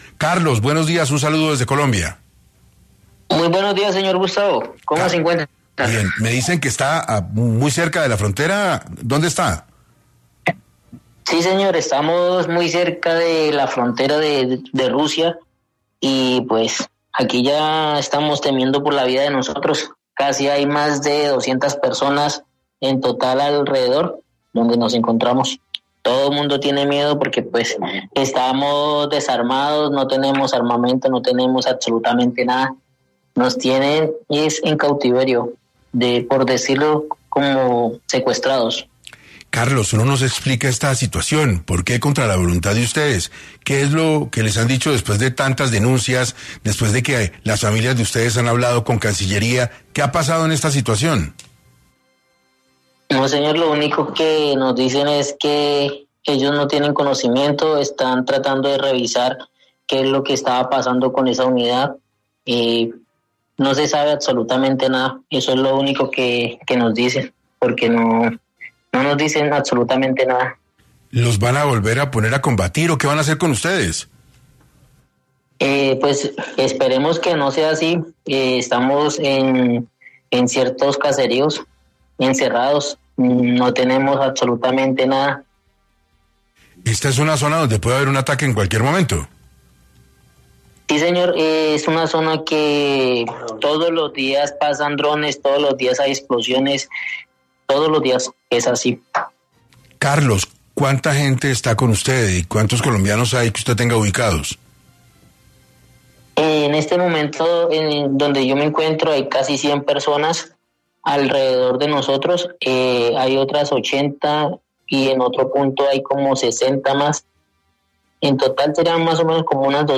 En entrevista con 6AM uno de esos soldados colombianos en Ucrania indica que se han empeorado sus condiciones luego que realizaron dichas denuncias.